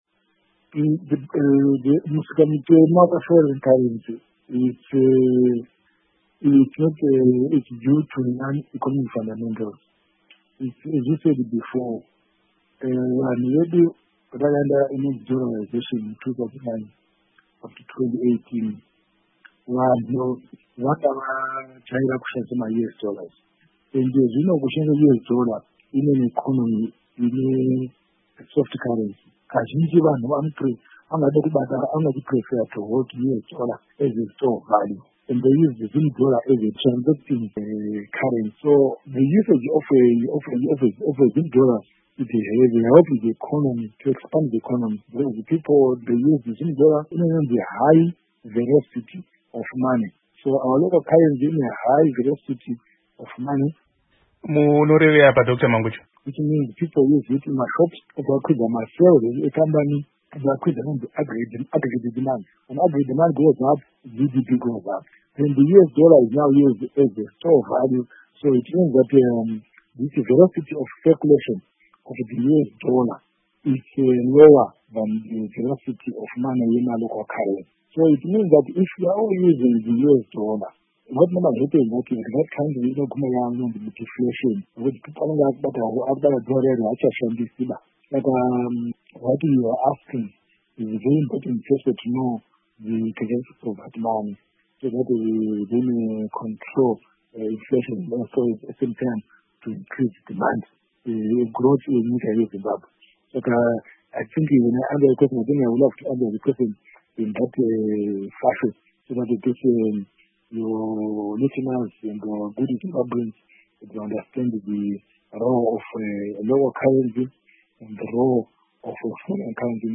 Hurukuro naDoctor John Mangudya